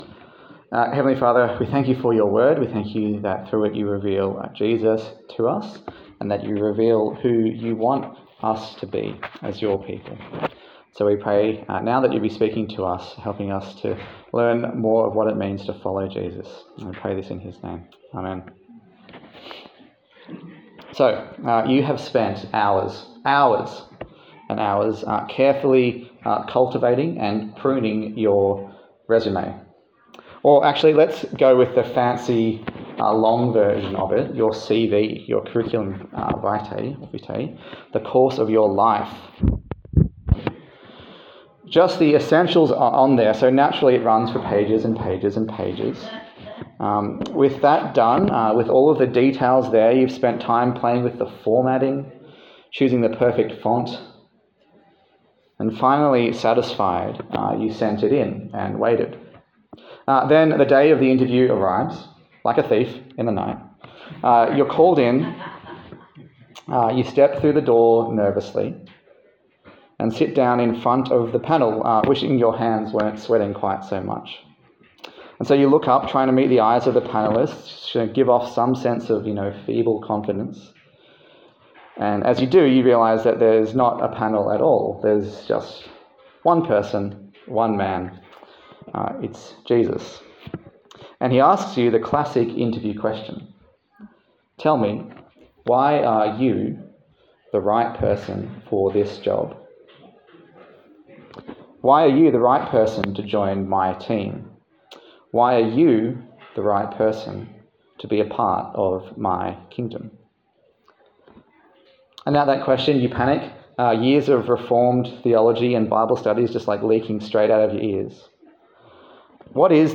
Luke Passage: Luke 18:1-19:10 Service Type: Sunday Service